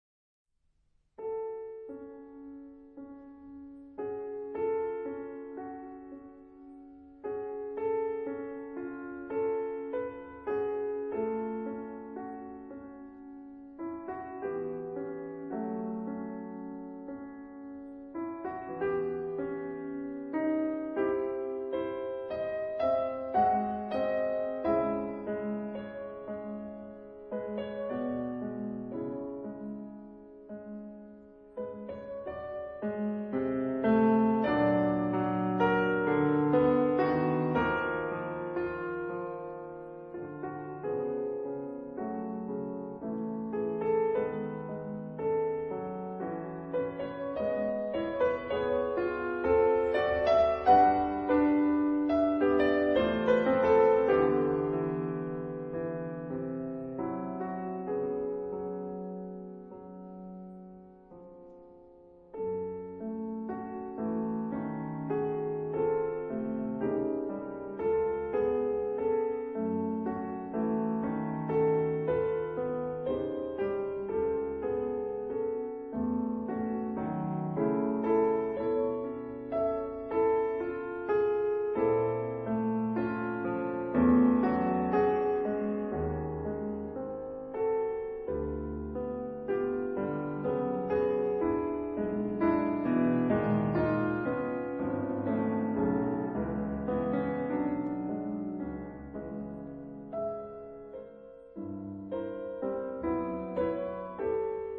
反而，收斂一下，帶了點陽光的感覺進來。
寂靜，有空間的沈思感。